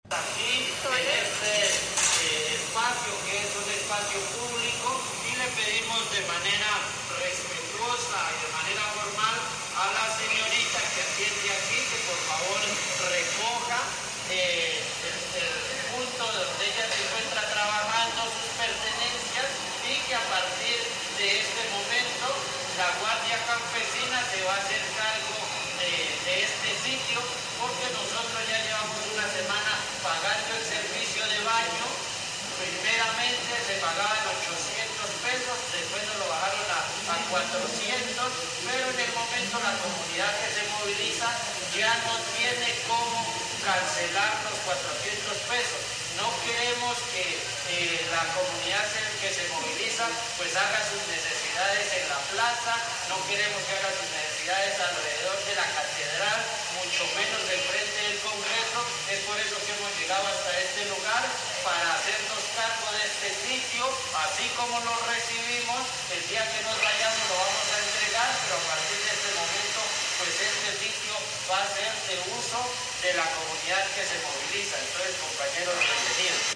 Caracol Radio conoció los audios del momento en el que la comunidad se toma los baños públicos.
“Le pedimos de manera respetuosa y de manera formal a la señorita que atiende aquí que por favor recoja el punto donde ella se encuentra trabajando, sus pertenencias y que a partir de este momento la guardia campesina se va a hacer cargo de este sitio”, dice un líder campesino a los jóvenes que administraban el servicio de baños.